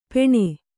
♪ peṇe